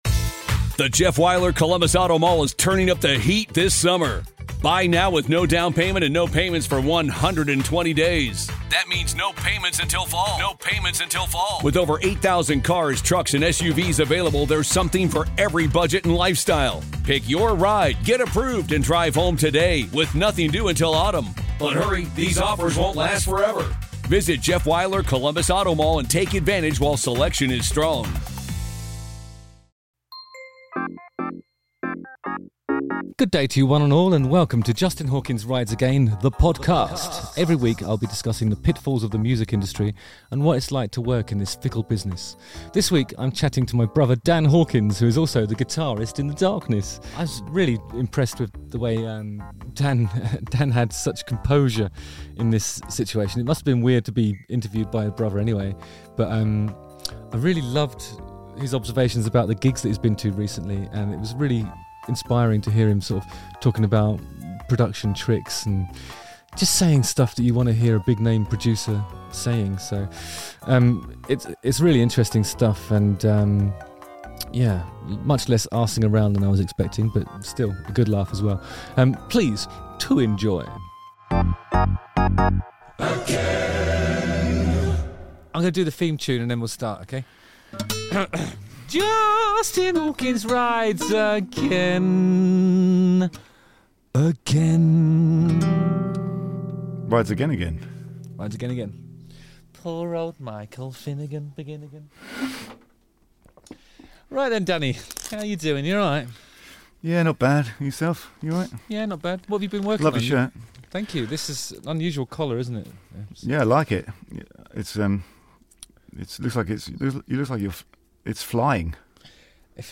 Interviewing My Legendary Brother, Dan Hawkins!